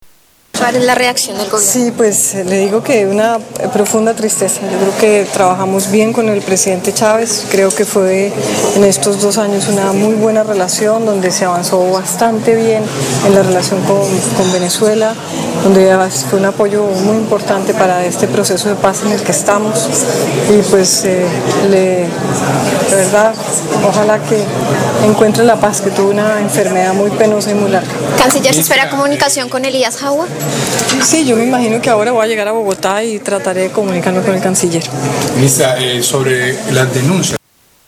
Escuche la declaración de la Ministra de Relaciones Exteriores María Ángela Holguín sobre el fallecimiento del Presidente Hugo Chávez